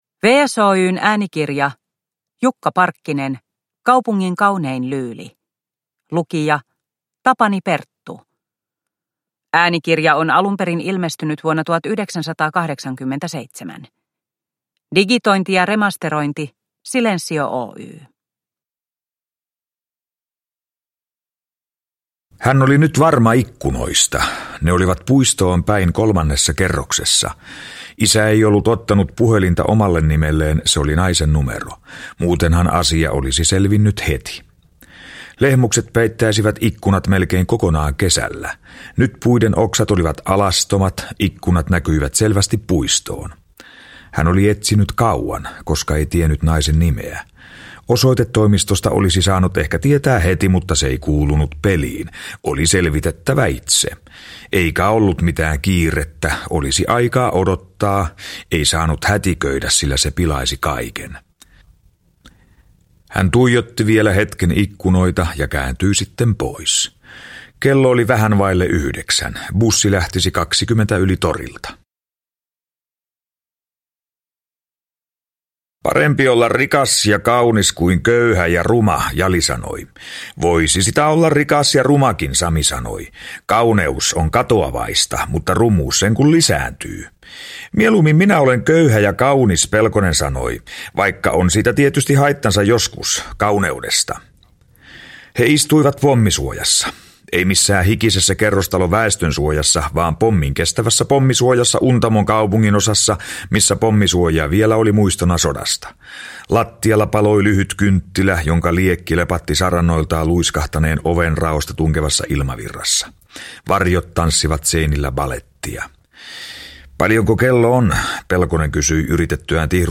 Kaupungin kaunein lyyli – Ljudbok – Laddas ner